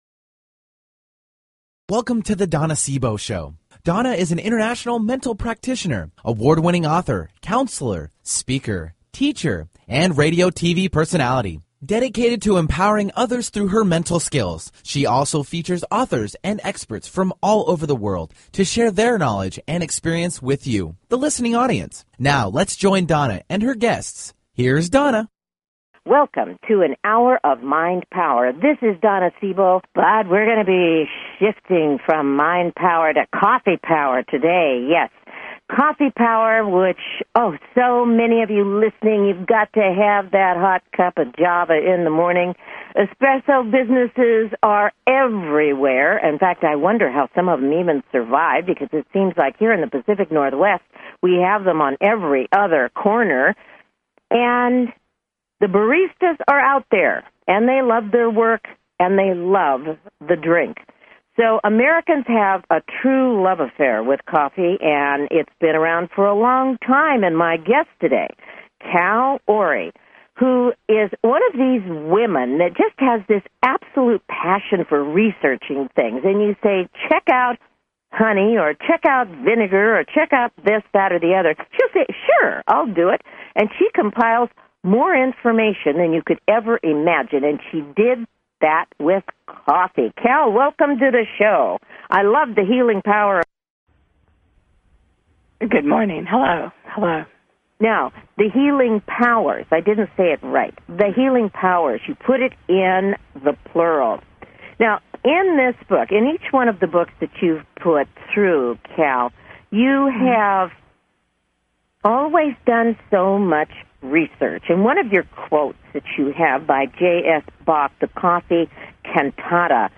Her interviews embody a golden voice that shines with passion, purpose, sincerity and humor.
Callers are welcome to call in for a live on air psychic reading during the second half hour of each show.